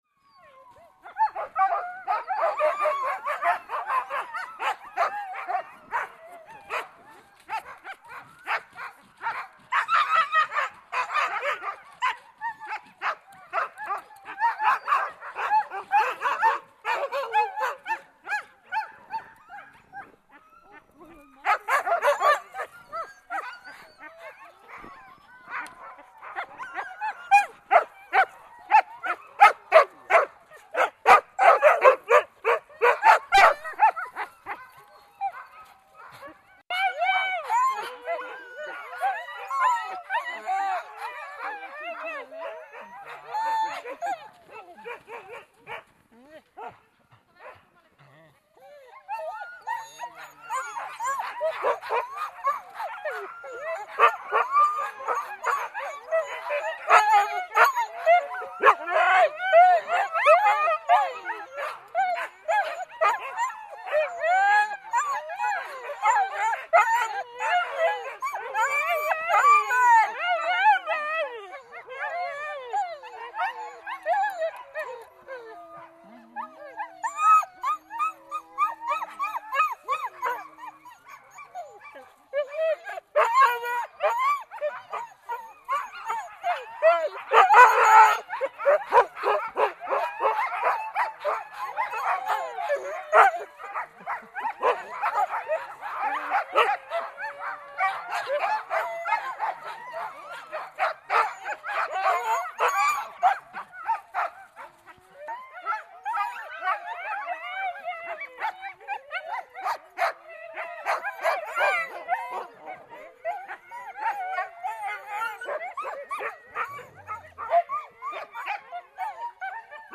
Soundrecordings of sledge dogs in Northern Finland
They have times of ceremonies when they sing or howl together for minutes.